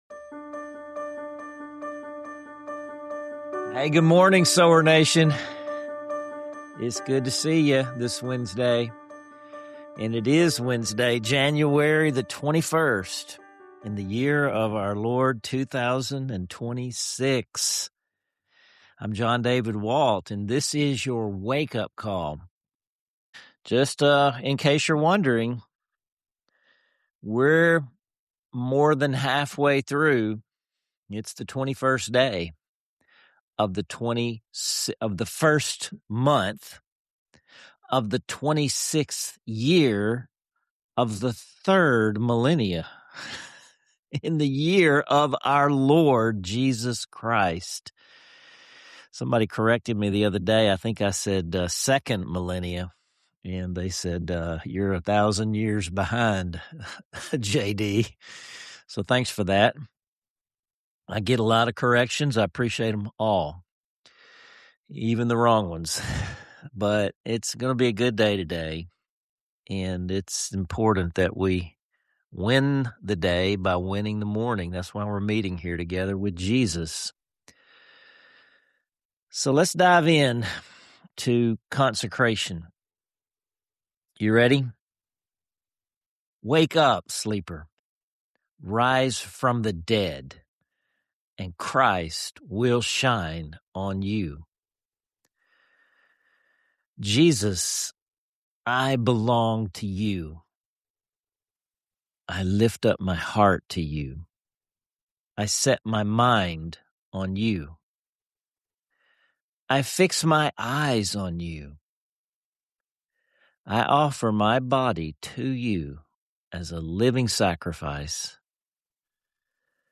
A special segment featuring a rousing father-son duet of the beloved hymn “Victory in Jesus,” guaranteed to uplift your spirit and remind you that we win not just someday, but today, because of Jesus.